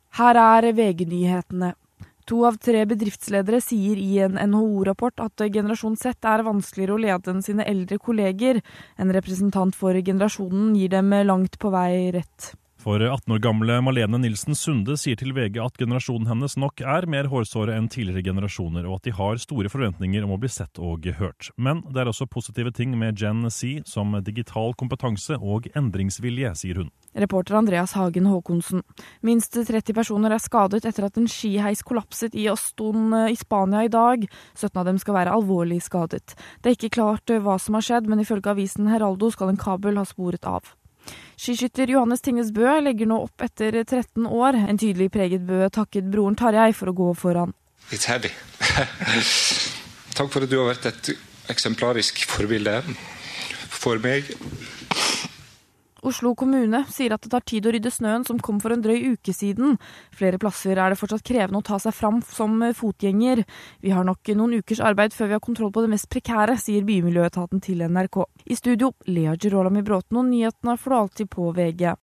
Hold deg oppdatert med ferske nyhetsoppdateringer på lyd fra VG. Nyhetene leveres av Bauer Media/Radio Norge for VG.